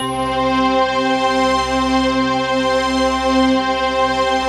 SI1 BELLS06L.wav